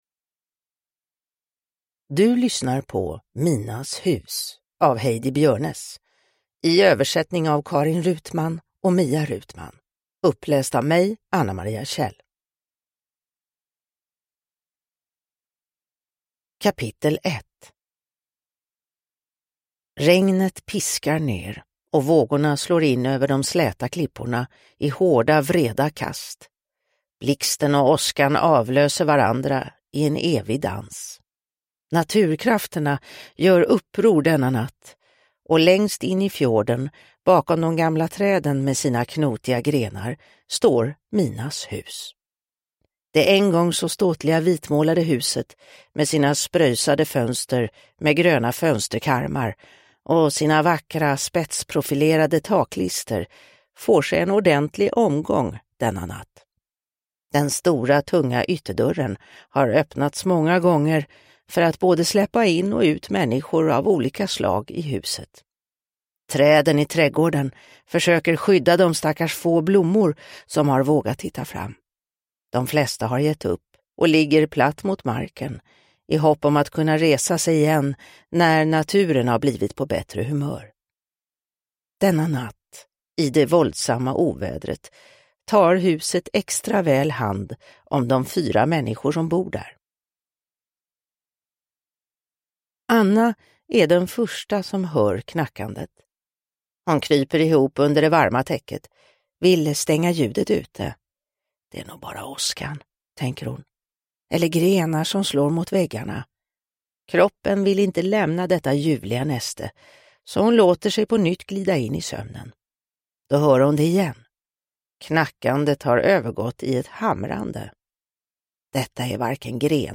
Minas hus – Ljudbok – Laddas ner